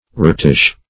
Routish \Rout"ish\